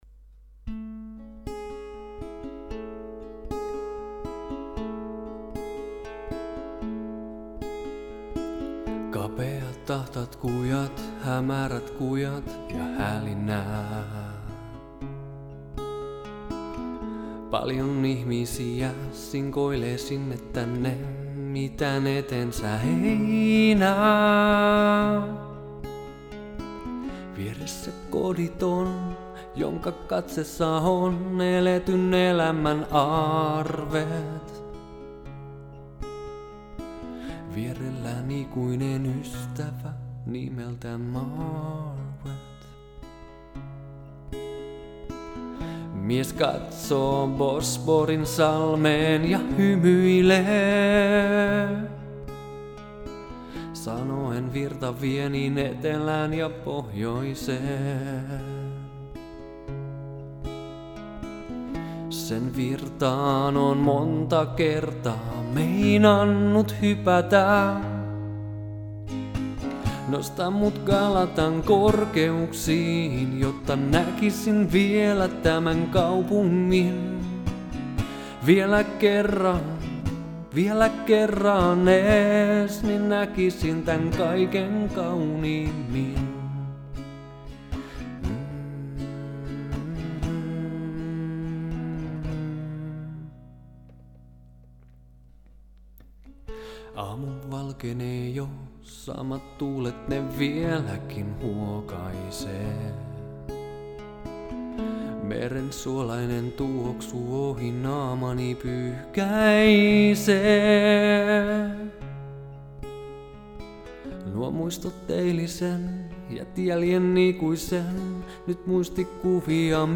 Laulu